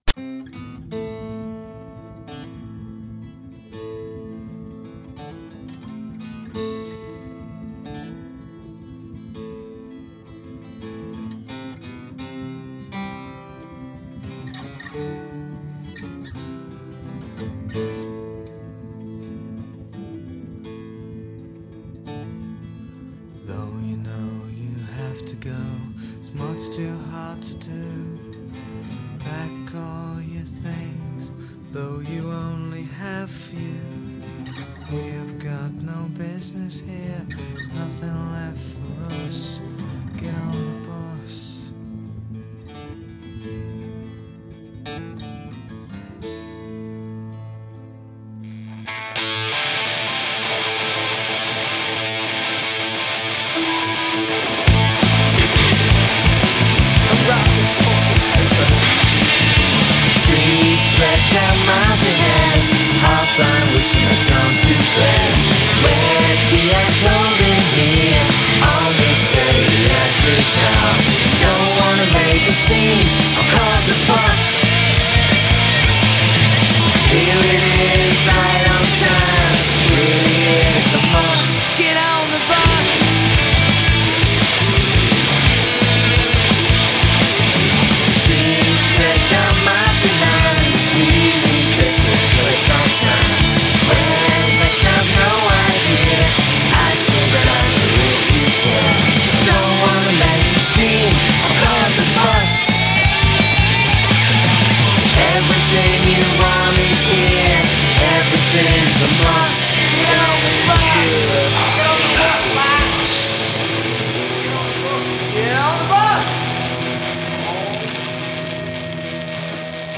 Groupe de rock anglais originaire de Liverpool.